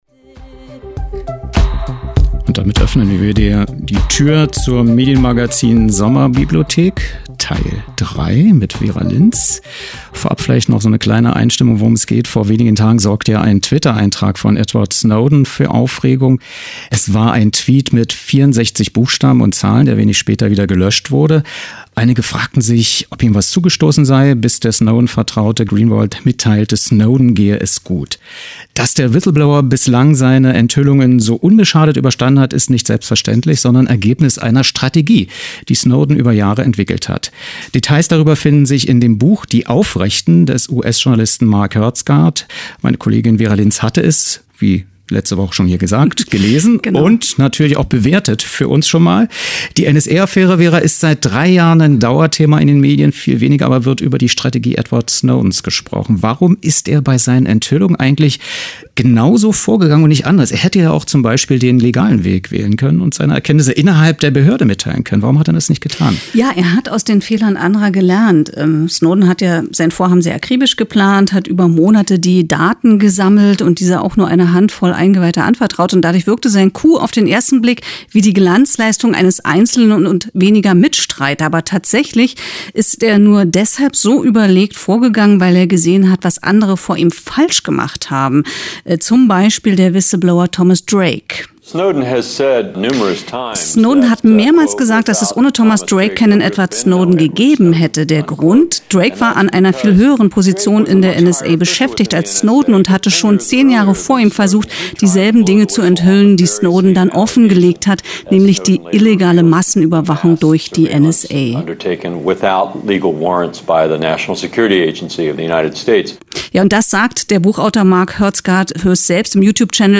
Was: Studiogespräch zum Buch
Wo: Medienstadt Potsdam-Babelsberg, rbb Radiohaus, radioeins-Senderegie